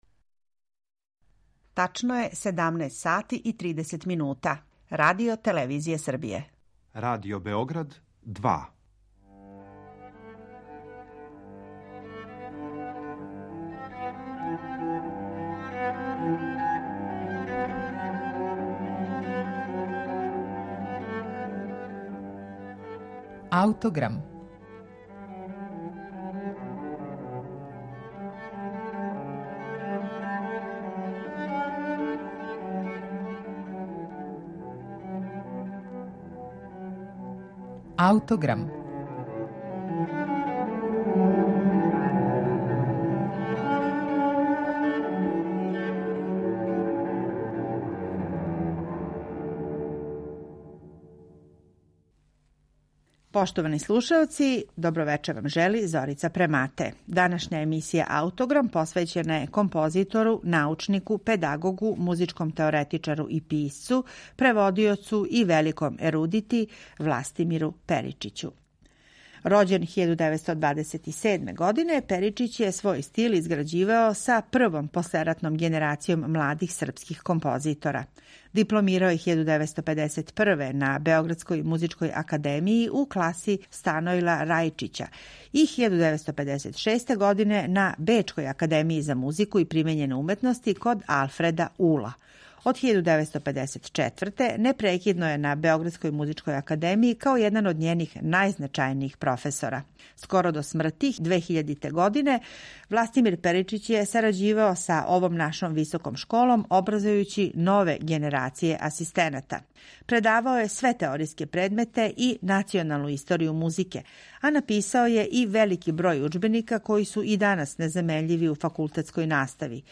Свира Симфонијски оркестар РТБ-а